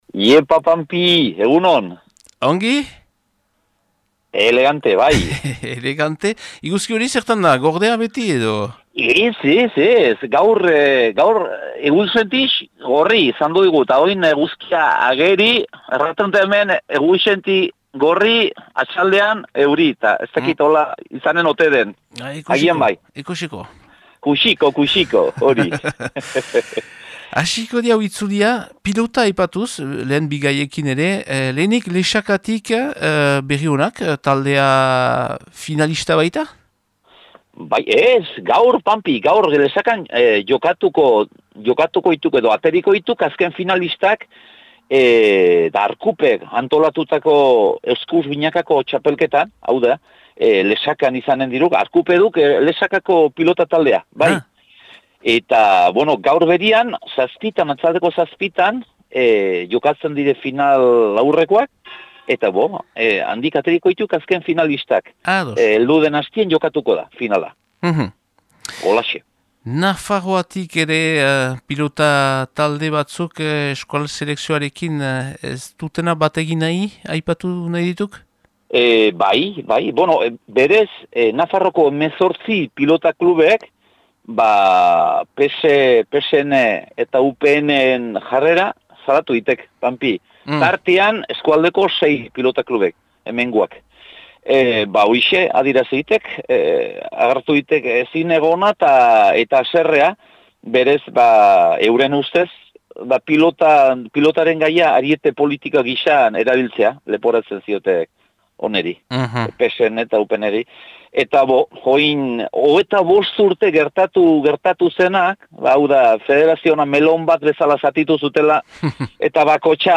Baztan eta inguruko berriak